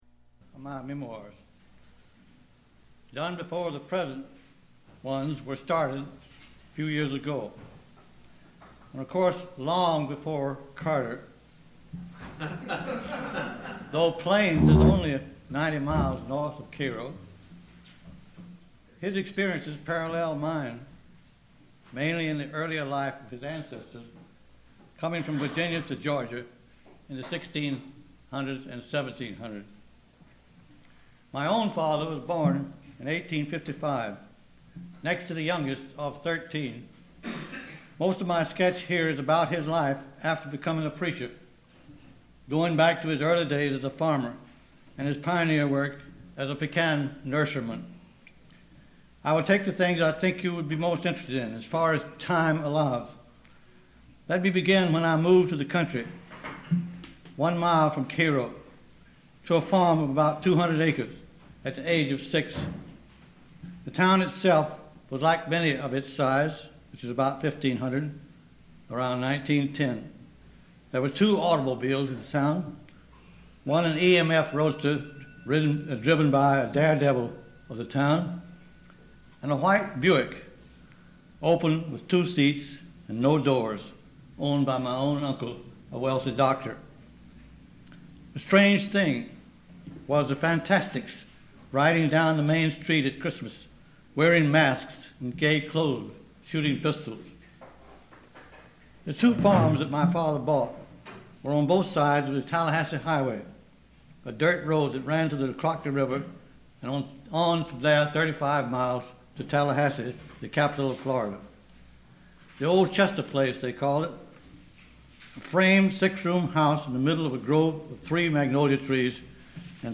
Four recorded talks to the Rowfant Club, probably in the 1980s